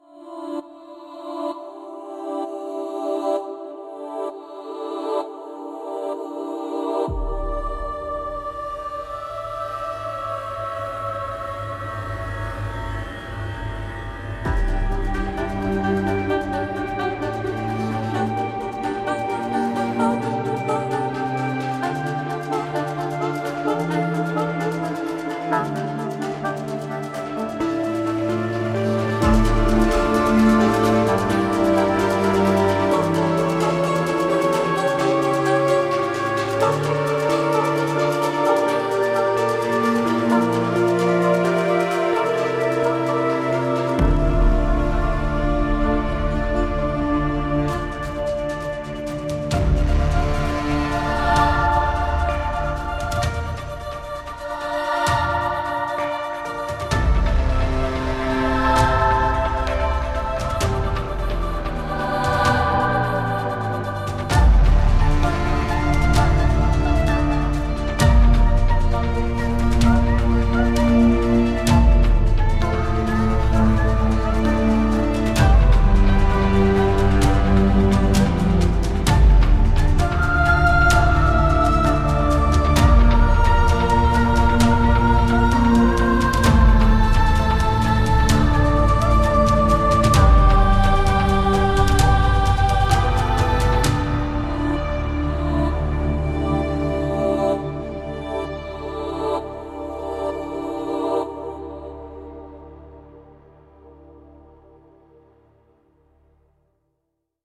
Mosaic Voices通过有机的男女合唱团，合成声垫以及复杂的噪音和无人机层重新构想了人声，提供鼓舞人心的声音和丰富的潜在运动。
从史诗到亲密，从合成到深情，Mosaic Voices是一款合唱团虚拟乐器，可提供专为现代乐谱开发的全套声源。
比其原始部分的总和更大， 马赛克之声 是人声合唱 VST，它分层了令人难以置信的来源，用合唱团的人声组成华丽、郁郁葱葱、有时令人难以忘怀的混合纹理。
凭借充满活力、有机的人声录音和合成人声，Mosaic Voices提供了一系列惊人的声音可能性，突破了电影作曲家用人声所能达到的界限。
有节奏的，抒情的，稀疏的，情感的，折衷的...可能性真的是无穷无尽的。